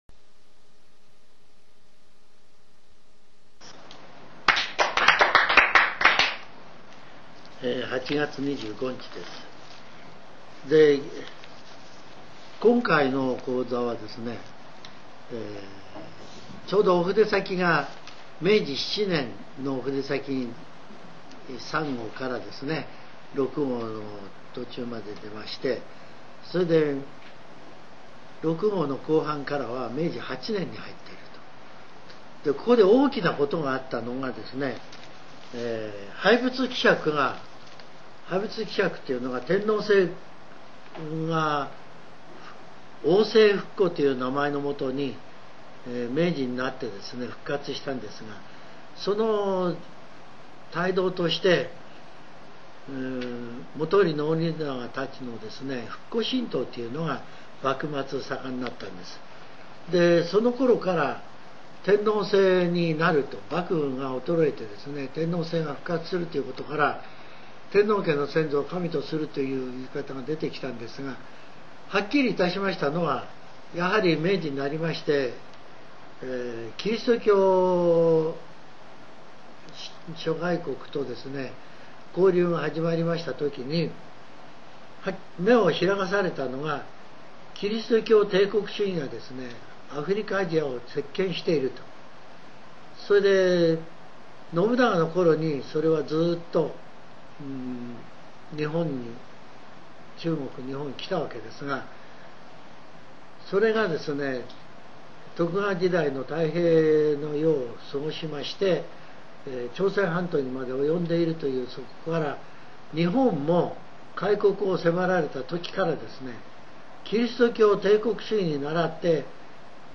全70曲中63曲目 ジャンル: Speech